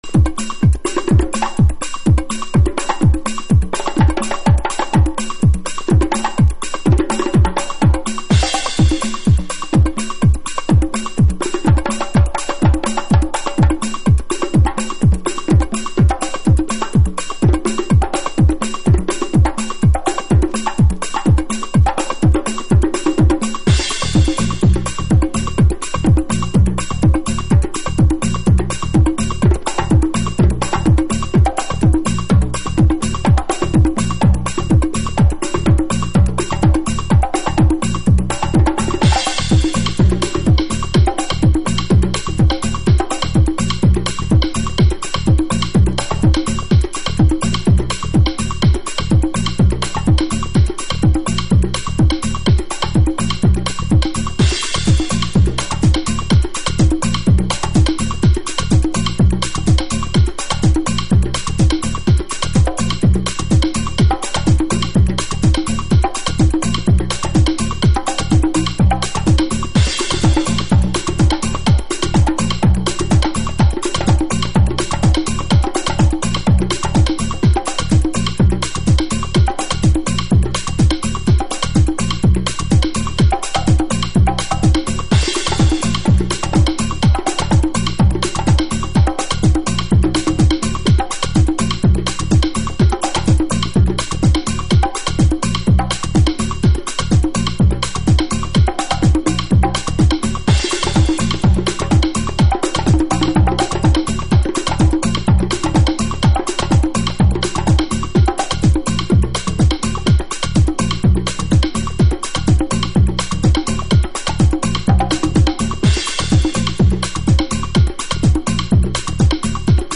House / Techno
パーカッション、スティールドラムはアコースティックでクオンタイズされたビートに独特のウネリを与えています。